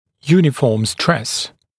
[‘juːnɪfɔːm stres][‘йу:нифо:м стрэс]равномерно распределенное давление (нагрузка)